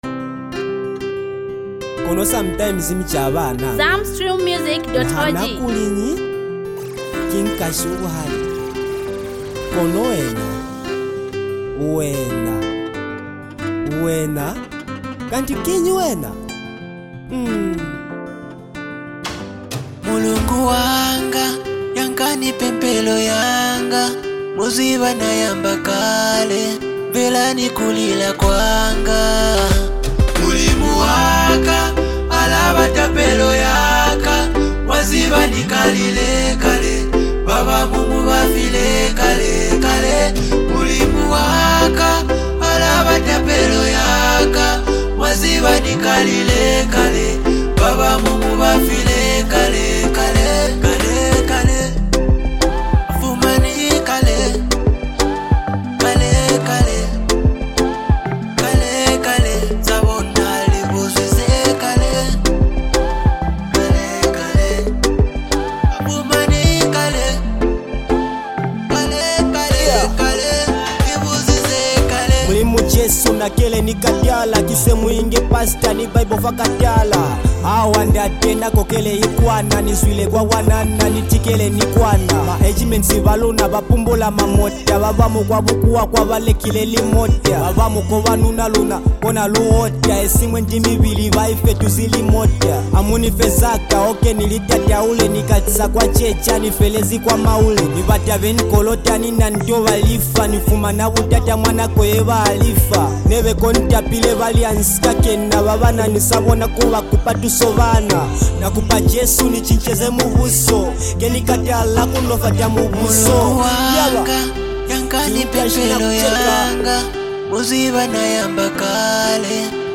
A compelling anthem born from rhythm, passion, and purpose
His voice carries command and conviction